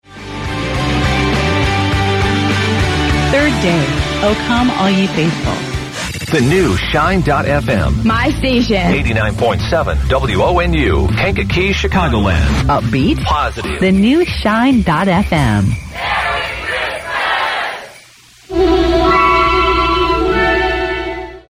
Positive Hit Music